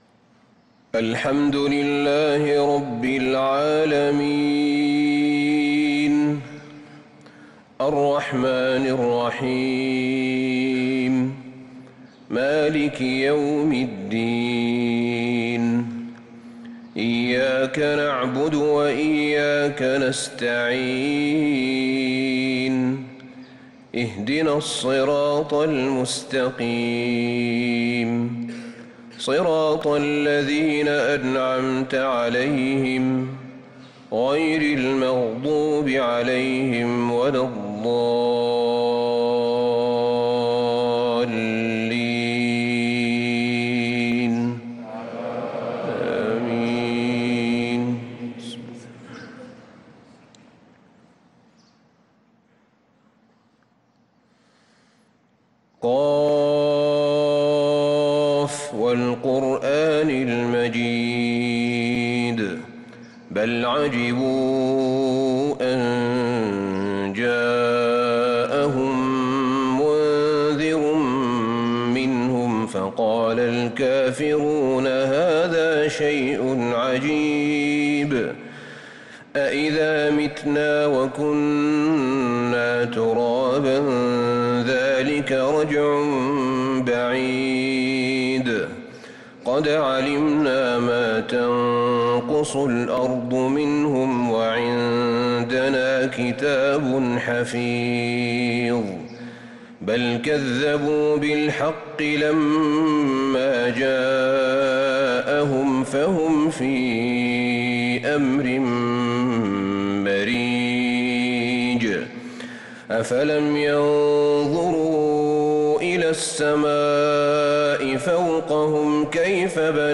فجر الاثنين 6-3-1446هـ  سورة ق كاملة | Fajr prayer from Surah Qaaf 9-9-2024 > 1446 🕌 > الفروض - تلاوات الحرمين